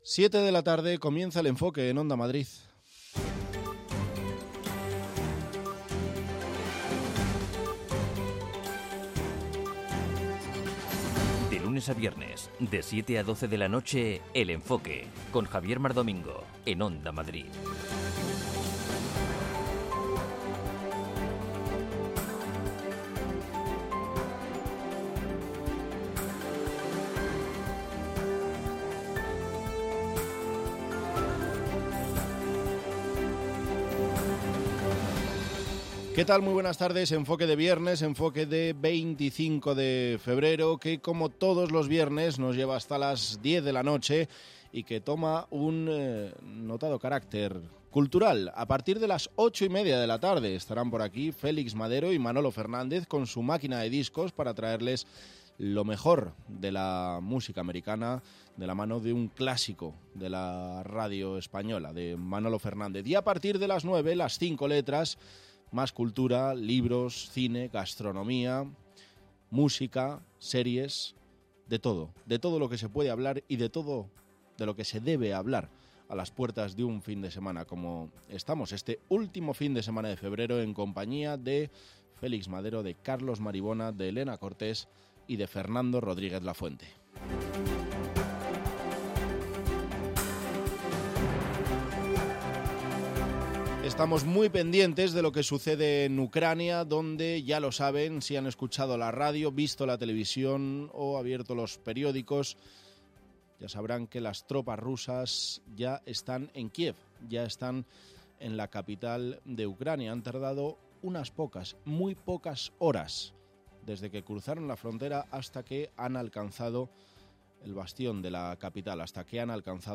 Cada tarde/noche a partir de las 19:00, Félix Madero da una vuelta a la actualidad, para contarte lo que ha pasado desde todos los puntos de vista. La información reposada, el análisis, y las voces del día constituyen el eje central de este programa, con la vista puesta en lo que pasará al día siguiente.